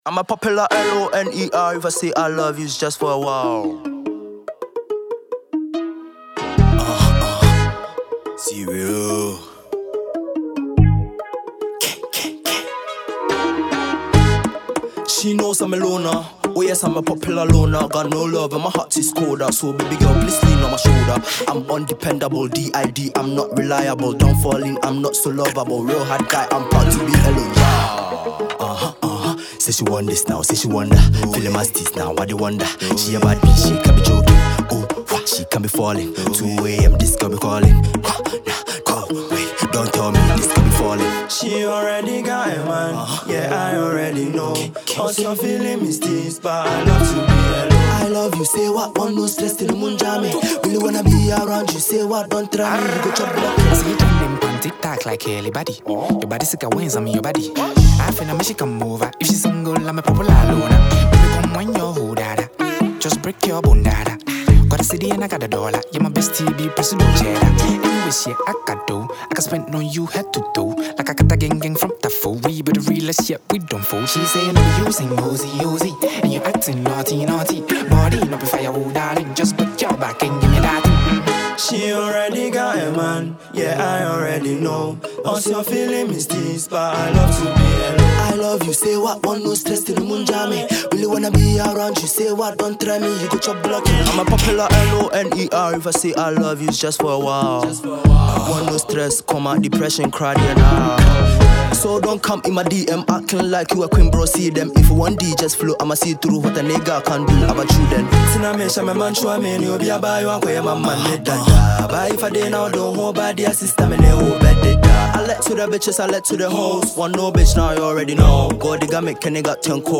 Ghanaian singer, songwriter, and producer
features the talented vocalist and entertainer